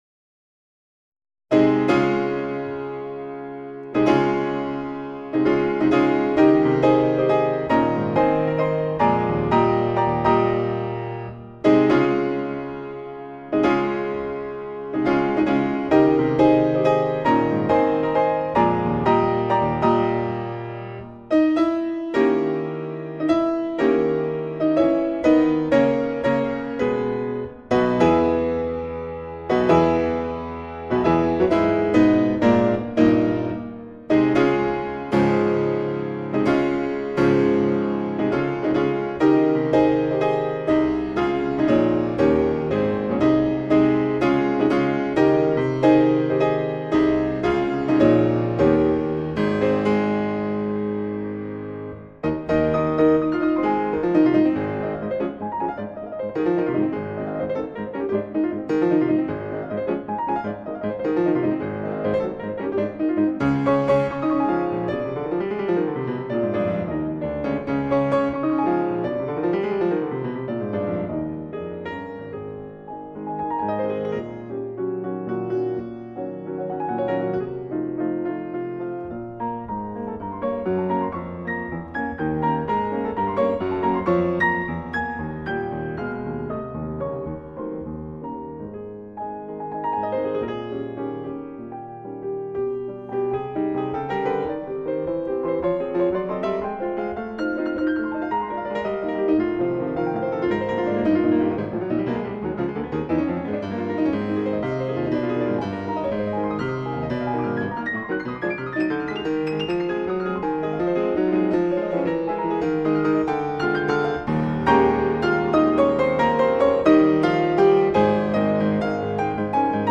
Iškilmingi fanfariniai akordai Įžangoje skelbia jo pradžią.